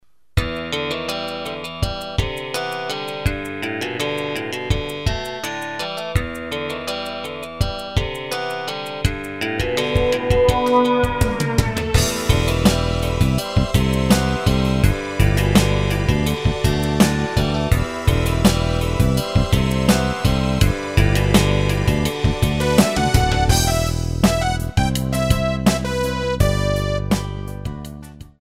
Tempo: 83 BPM.
MP3 with melody DEMO 30s (0.5 MB)zdarma